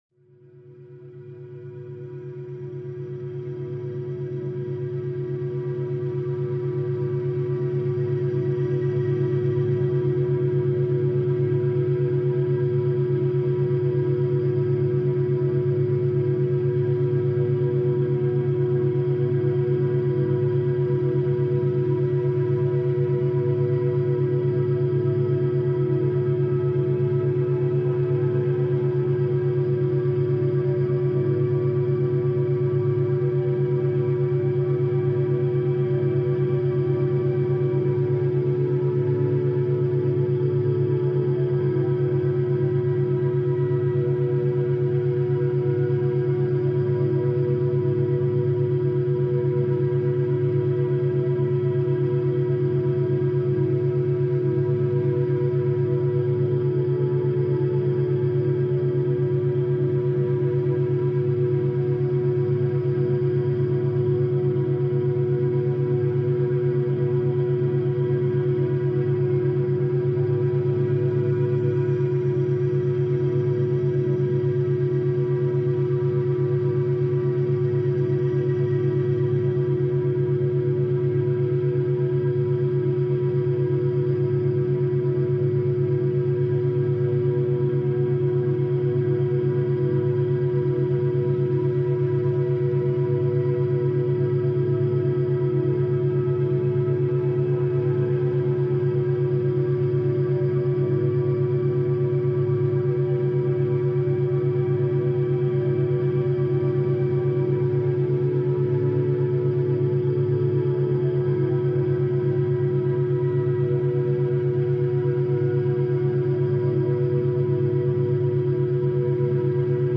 Mozart Piano : Focus Relaxant
Aucun bruit parasite, aucune coupure soudaine.